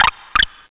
pokeemerald / sound / direct_sound_samples / cries / deerling.aif
deerling.aif